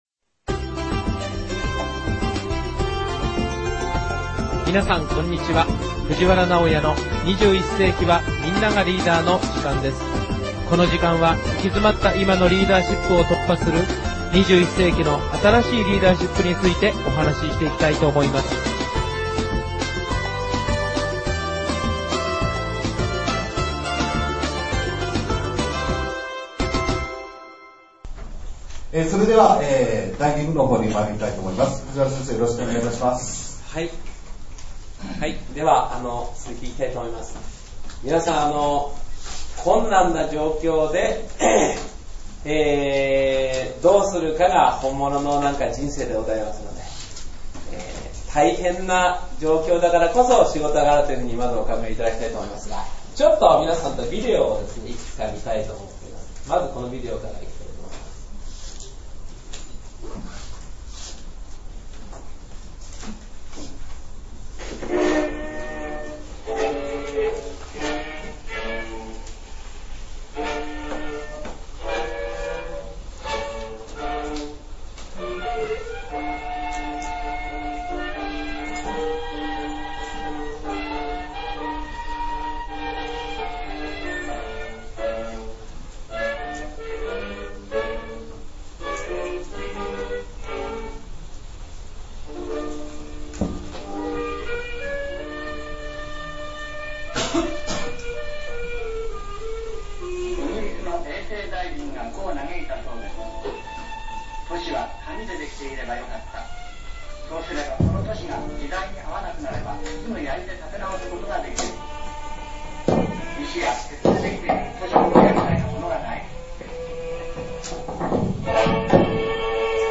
講座第19回